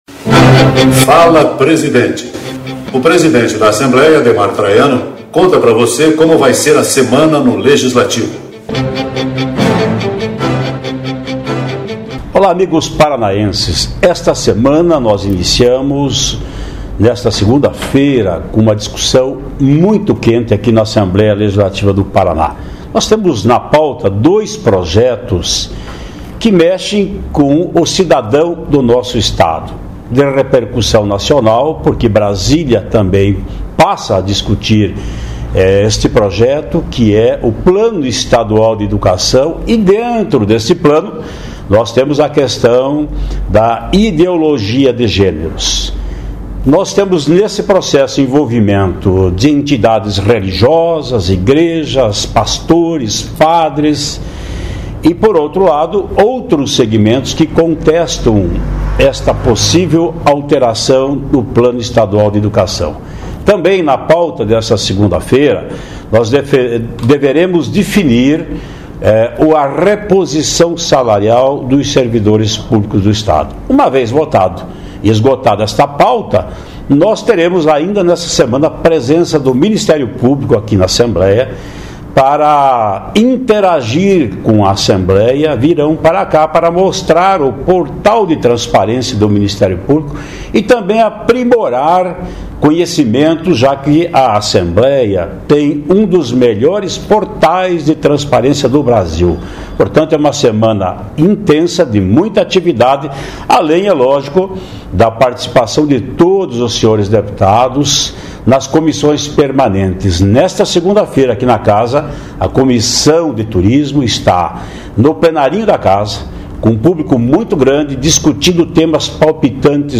No "Fala Presidnte" de hoje o presidente Ademar Traiano adianta pra você as notícias da Assembleia